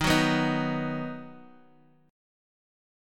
D#m#5 chord